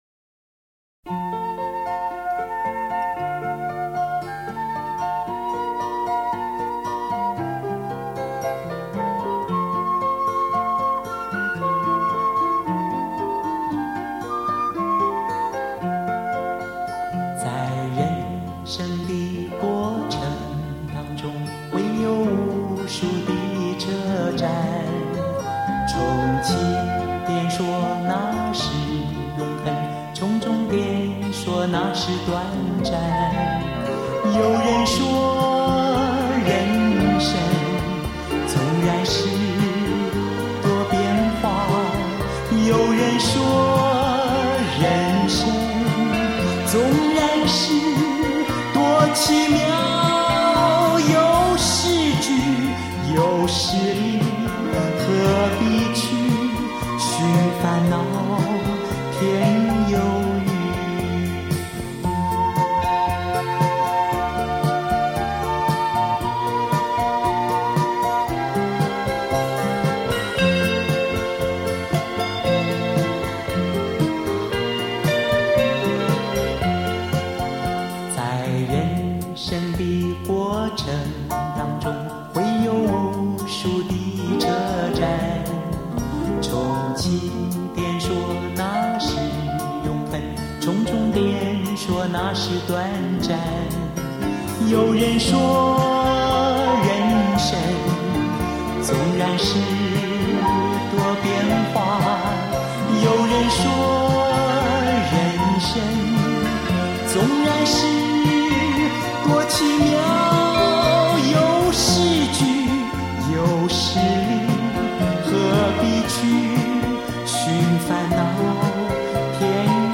华语怀旧